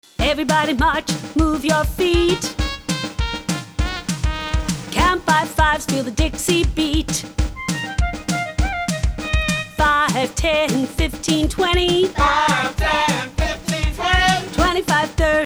Skip-Counting Song
This puts the beats in sets of 2 or 4.